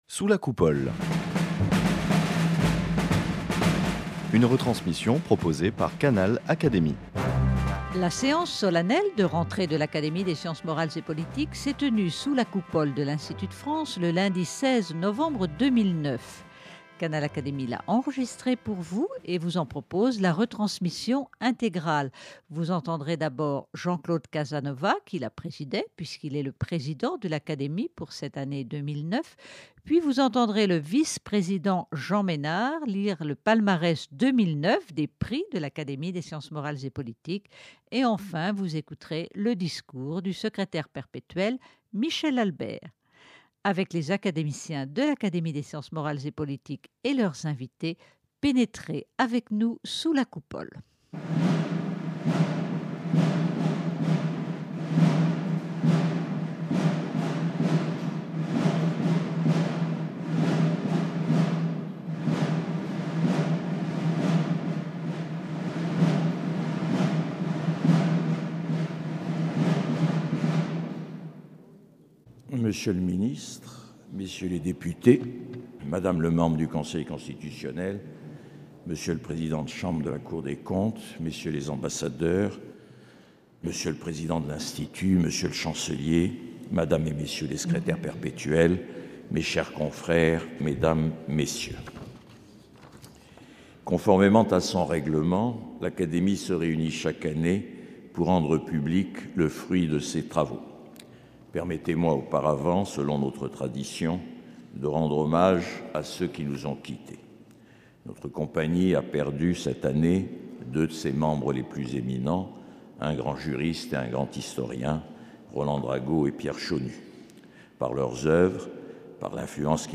La séance solennelle de rentrée de l’Académie des sciences morales et politiques s’est tenue, sous la Coupole de l’Institut de France, le lundi 16 novembre 2009
Vous entendrez dans l’ordre, le président Jean-Claude Casanova, la lecture du palmarès des prix et médailles par Jean Mesnard, le discours du Secrétaire perpétuel Michel Albert.
Ceux-ci sont, comme vous pouvez l'entendre, chaudement applaudis par les invités qui ont pris place sous la Coupole.